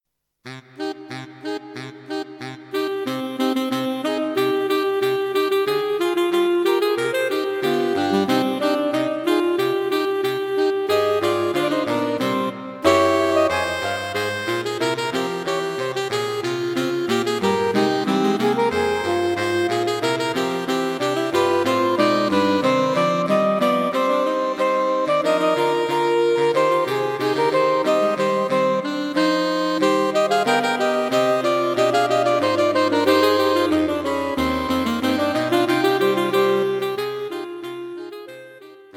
Saxophone Quartet for Concert performance